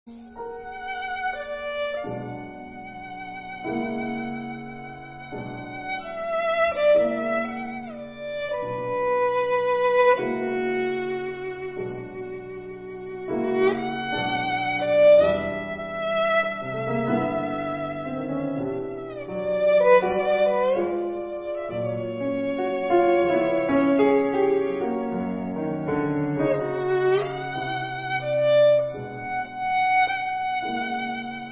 מצרפת כמה קבצי שמע של הסגנון שהתכוונתי (באיכות נמוכה, כי לא עולה פה..)